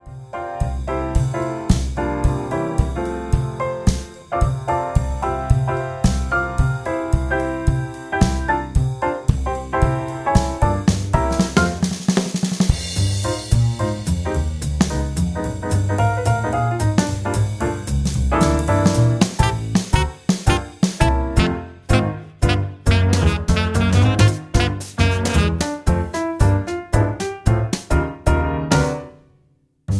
Backing track Karaoke
Pop, Jazz/Big Band, 2000s